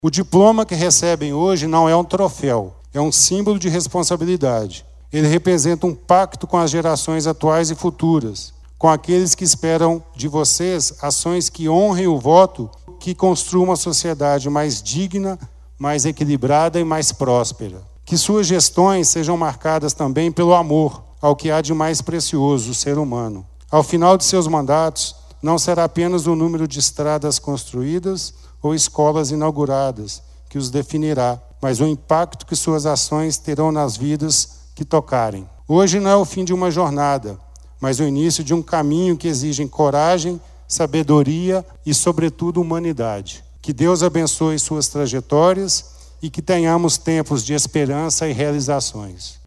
A diplomação dos eleitos ocorreu na noite desta sexta-feira (13), na Câmara Municipal de Pará de Minas, em solenidade presidida pela juíza Gabriela Andrade de Alencar Ramos.
O representante do Ministério Púbico Eleitoral também alertou aos eleitos, que o diploma que receberam não é um troféu, e sim um símbolo de responsabilidade: